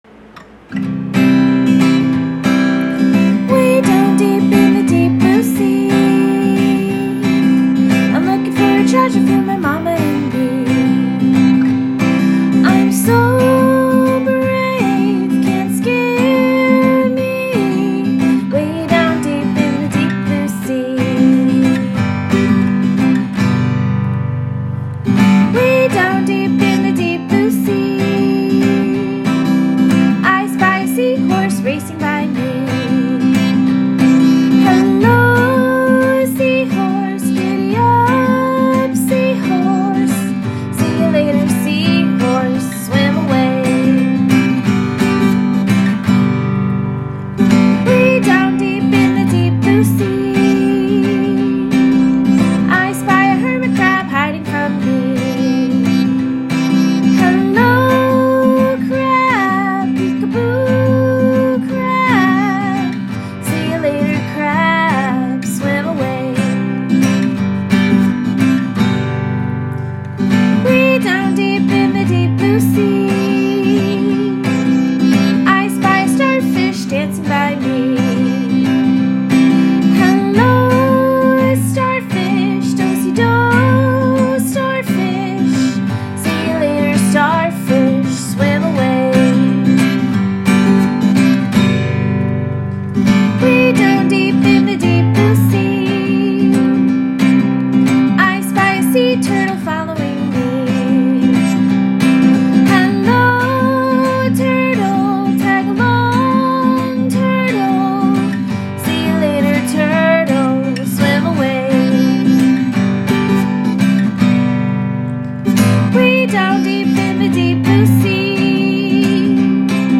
Feel free to sing along with the recording or create your own melody!
This is a great opportunity for younger children to practice singing “hello” and “goodbye.”